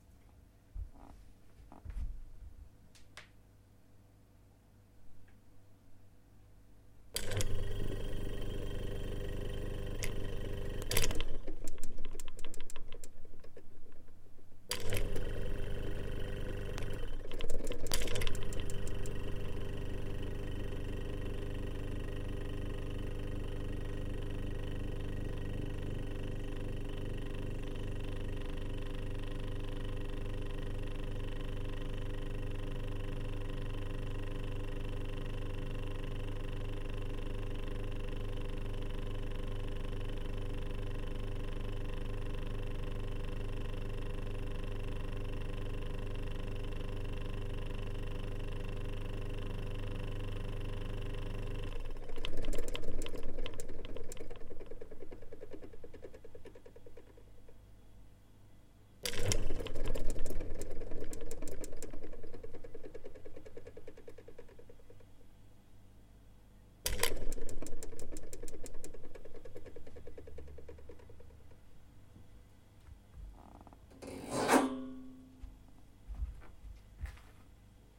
冰箱 " Oldestfridge(3)
描述：旧苏联冰箱。
Tag: 厨房 冰箱 电机 房子记录 冰箱 国内 发动机